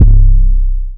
808 (LockDown).wav